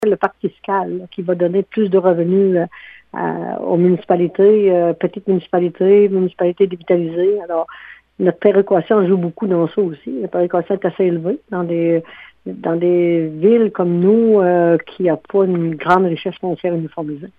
La hausse du budget est attribuable à la signature du Pacte fiscal entre le Gouvernement du Québec et les villes et municipalité du Québec, comme le précise la mairesse, Délisca Ritchie-Roussy :